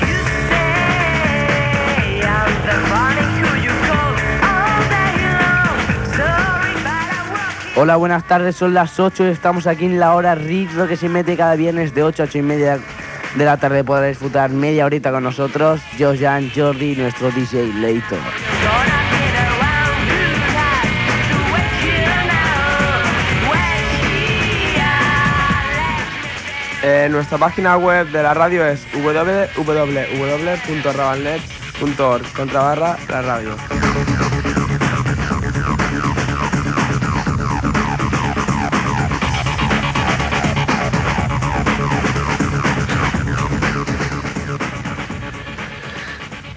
89d43e0c67a095a67b04e1bdf3726a7da37fe0e1.mp3 Títol Ràdio Ravalnet Emissora Ràdio Ravalnet Titularitat Tercer sector Tercer sector Barri o districte Nom programa La hora rizo Descripció Identificació del programa i adreça web.